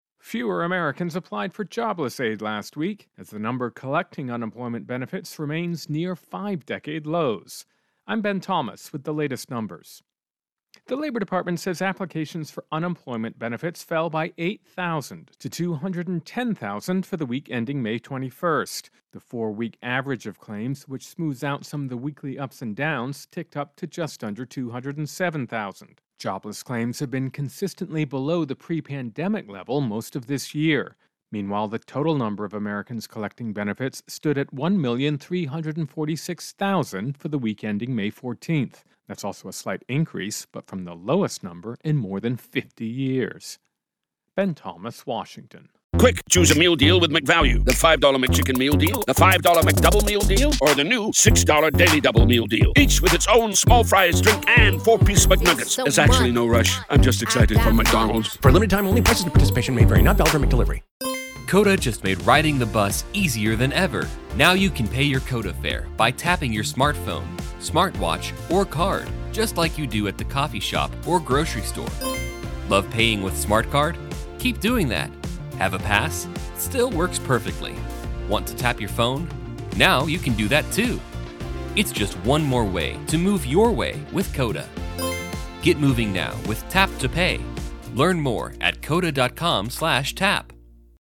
Intro and voicer "Unemployment Benefits"